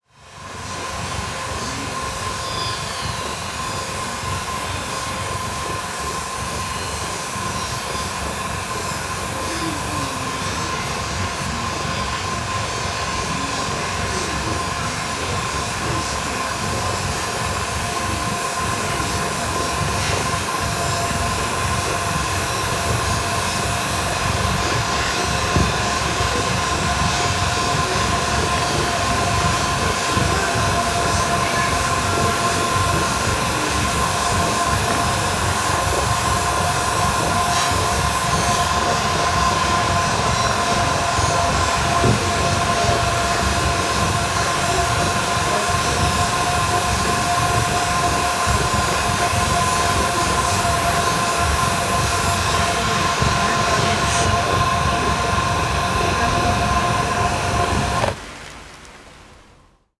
The album is a collection of edited field recordings dealing with the concept of affective atmosphere and human/listener positioning within the sonic environment.
The recordings were taken in Sao Miguel, Azores (Portugal) in 2017, where I travelled to join the conference “Invisible places. Sound, Urbanism, and sense of place”. After the conference, I went on a field recording trip through the island, exploring the Volcanos, shores and geothermal steam sites.
As the human element is reduced, listeners may make a connection between the droning sounds of nature and those of factories.